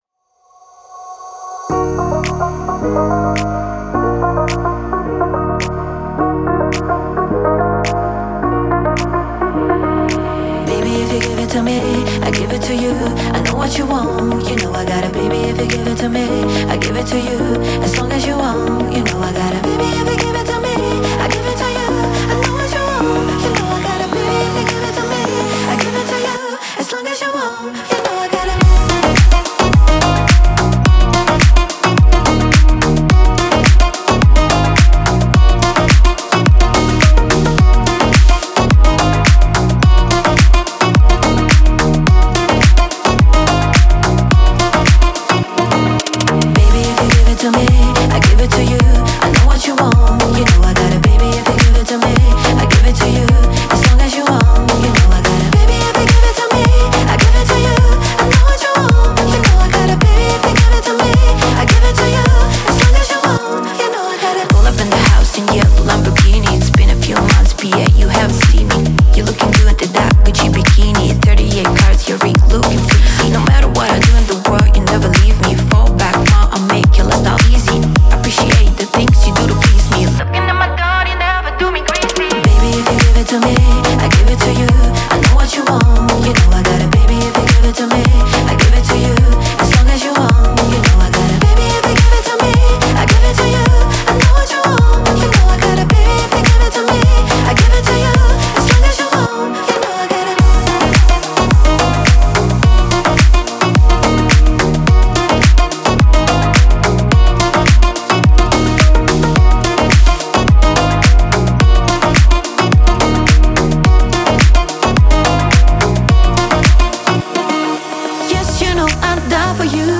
Категория: Танцевальная музыка
dance треки